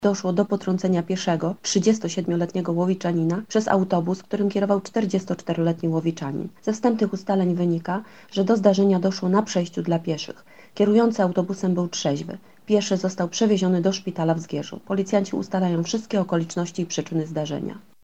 Okoliczności wypadku wyjaśnia łowicka policja.